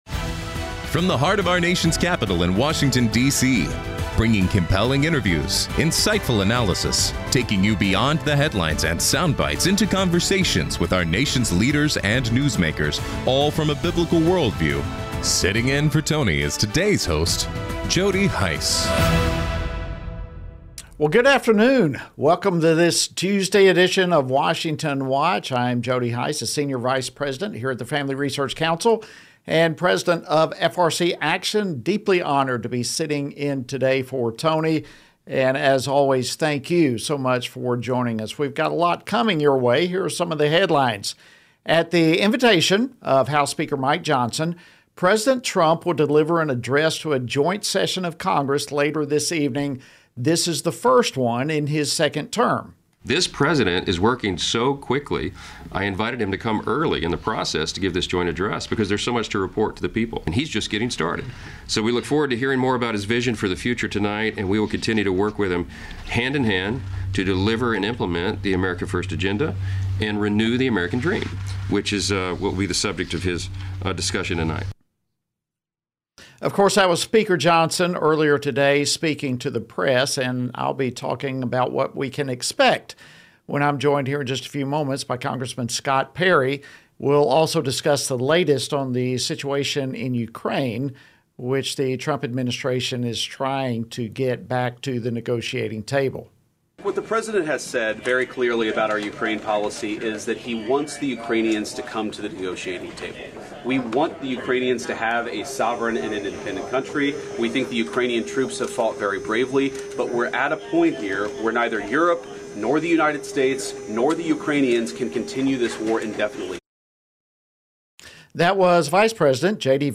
Burgess Owens, U.S. Representative for Utah’s 4th District, comments on the Senate confirmation of Linda McMahon as Secretary of Education and efforts to co